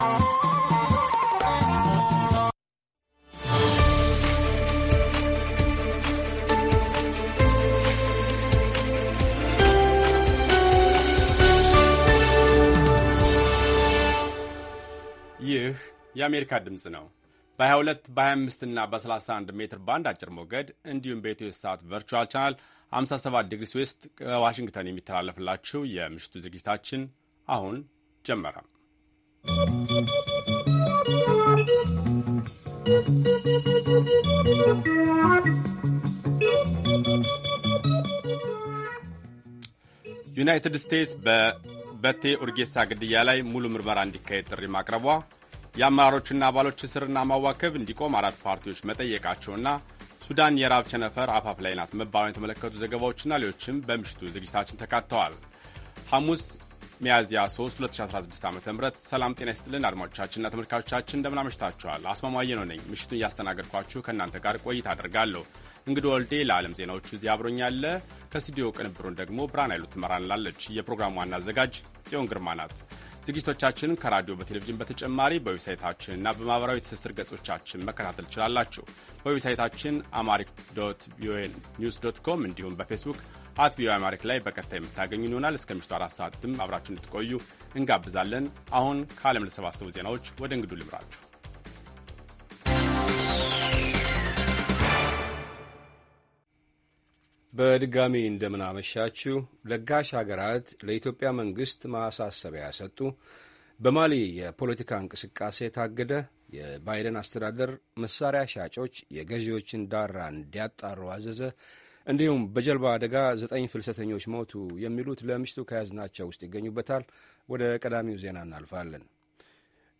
ሐሙስ፡-ከምሽቱ ሦስት ሰዓት የአማርኛ ዜና